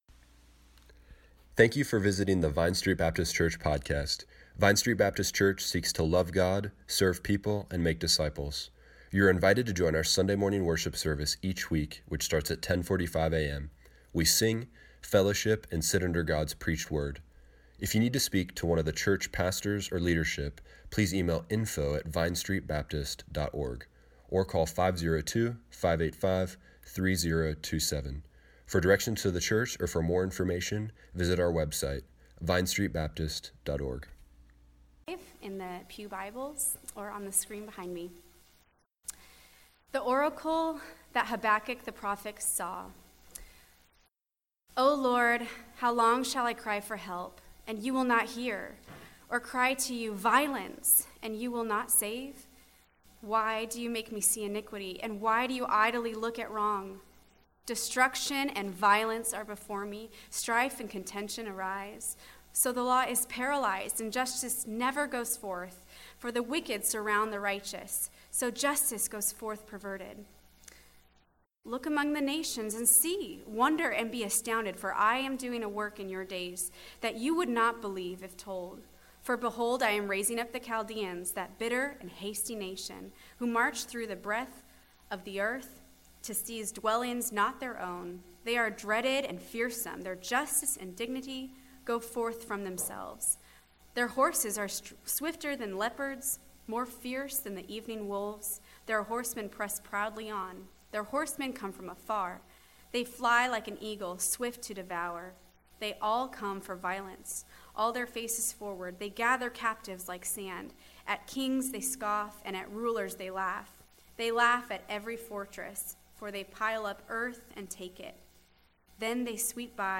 Service Morning Worship Tweet Summary February 16, 2020 1. Amid times of evil, Habakkuk cries for help and God seems silent. 2.